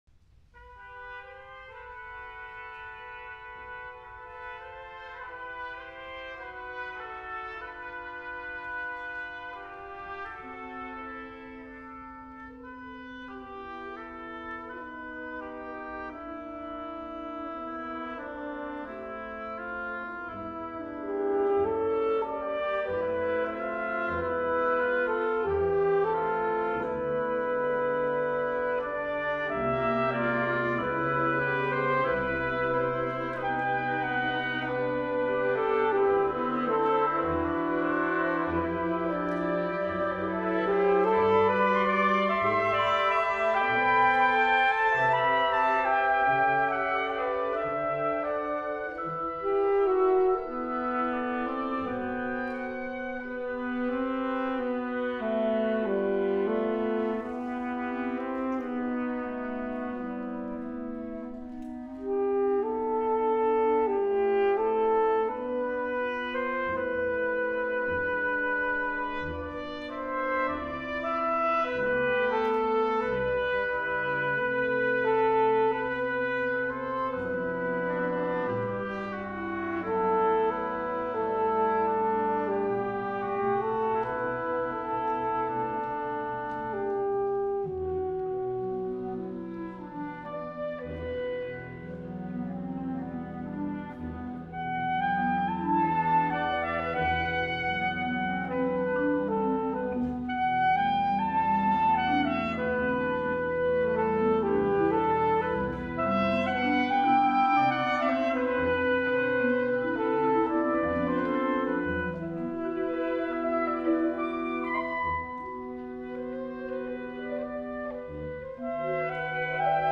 for Solo Alto Saxophone and Wind Ensemble
KHS Hall, Luzou, New Taipei City, Taiwan
alto saxophone
This is the world premiere recording.